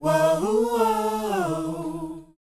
WHOA E A.wav